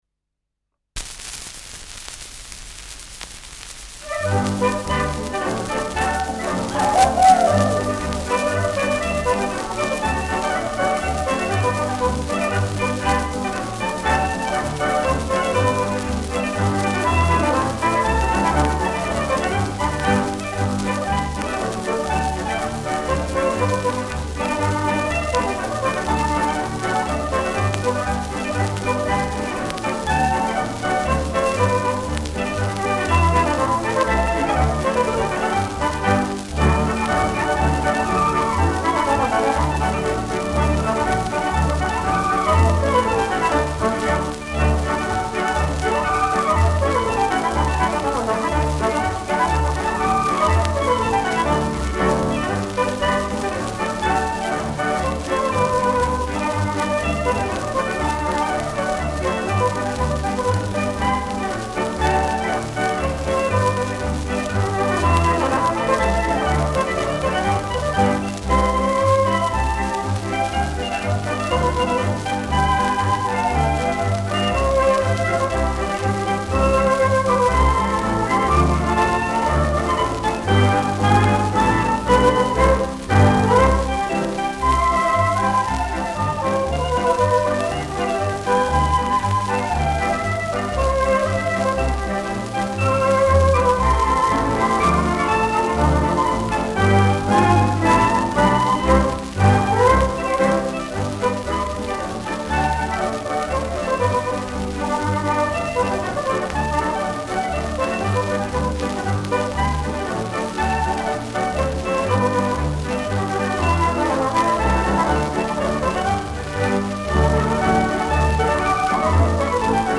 Schellackplatte
präsentes Knistern
Stocker Sepp's I. Unterwaldner Bauernkapelle (Interpretation)
Mit Juchzern.
Ländlerkapelle* FVS-00018